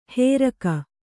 ♪ hēraka